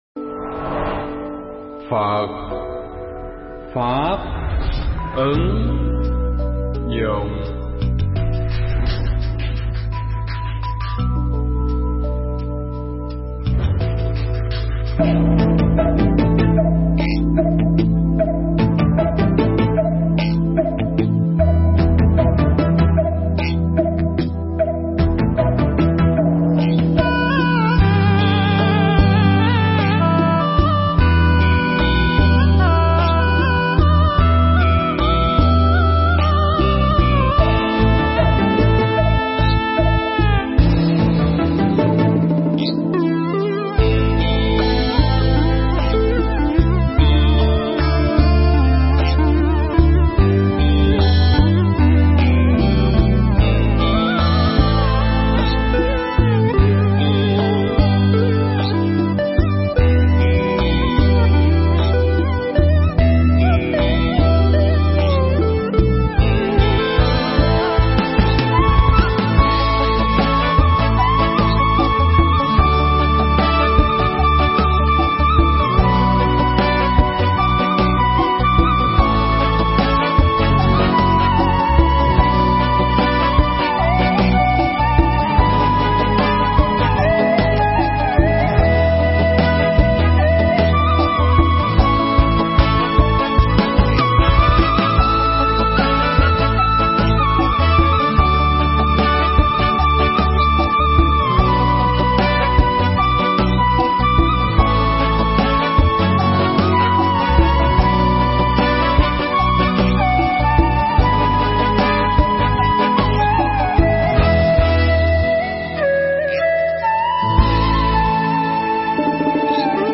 Bài thuyết pháp